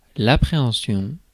ÄäntäminenQuébec:
• IPA: [l‿a.pʁe.ɑ̃.sjɔ̃]